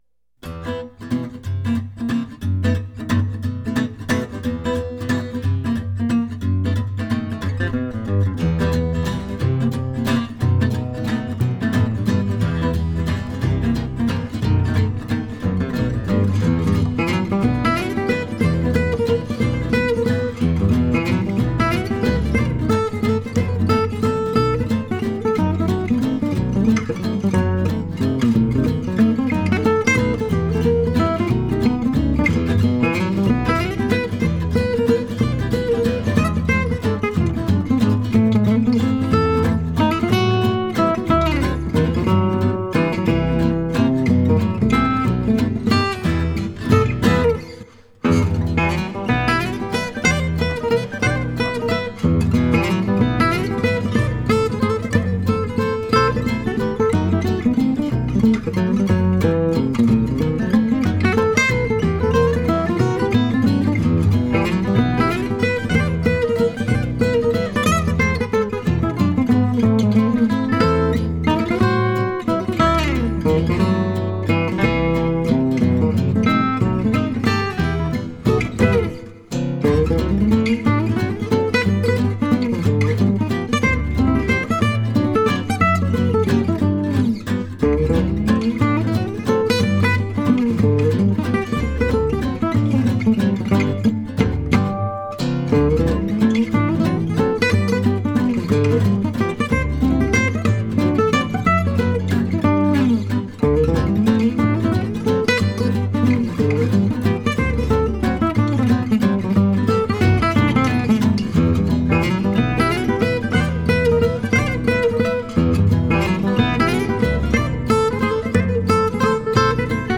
jazz group